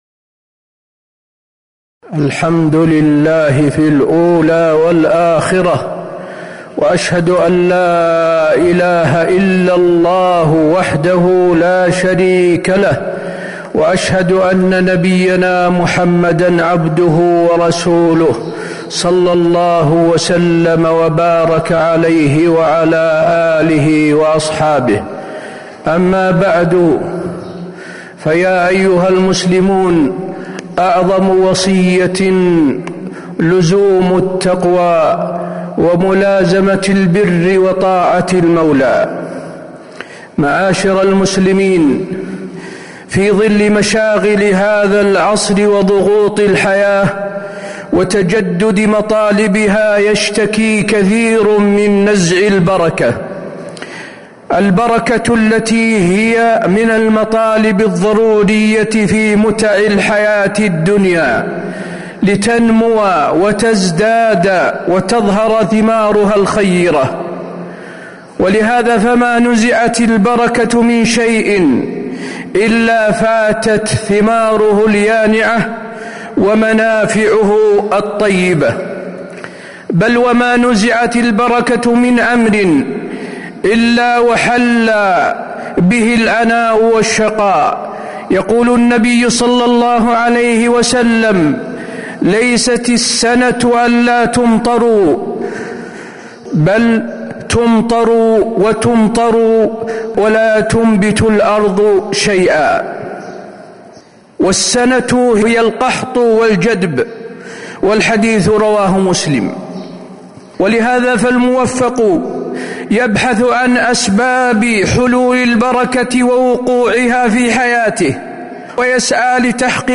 تاريخ النشر ٢٣ جمادى الآخرة ١٤٤٥ هـ المكان: المسجد النبوي الشيخ: فضيلة الشيخ د. حسين بن عبدالعزيز آل الشيخ فضيلة الشيخ د. حسين بن عبدالعزيز آل الشيخ من أسباب نزع البركة